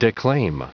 Prononciation du mot declaim en anglais (fichier audio)
Prononciation du mot : declaim